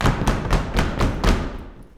121 STOMP3-R.wav